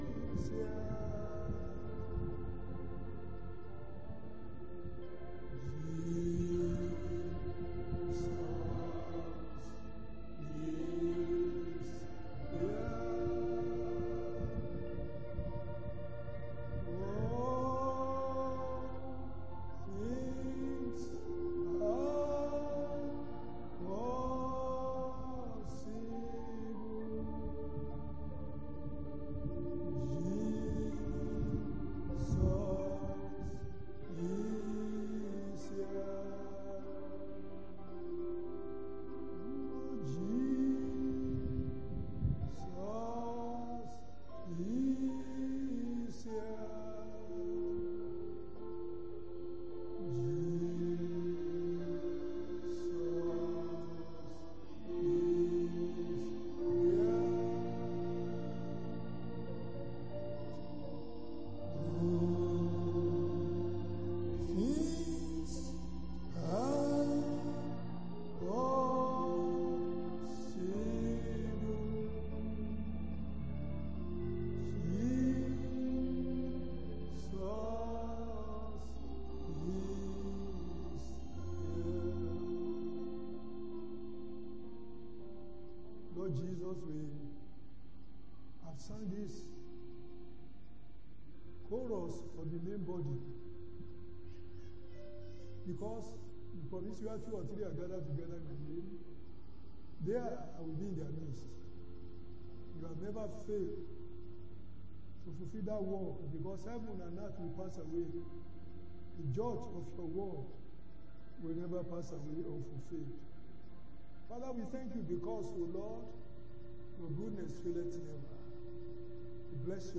Sunday Afternoon Service